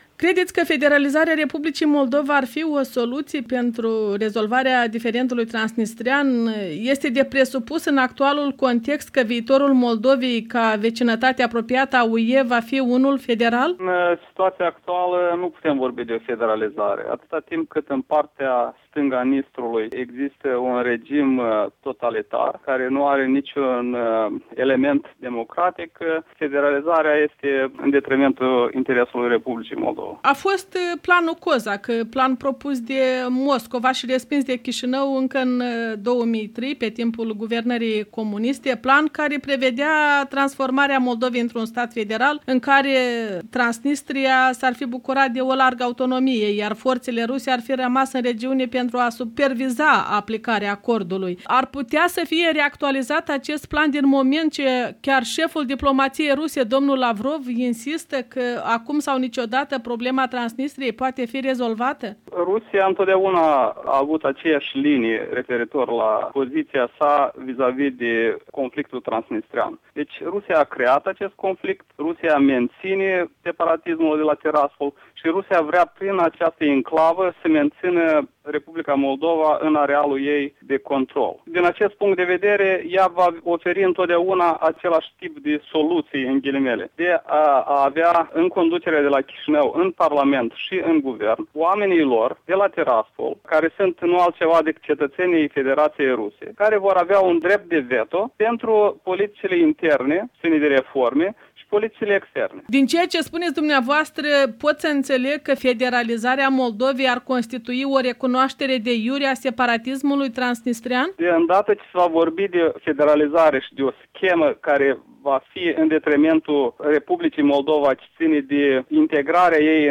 In direct de la Washington o discuție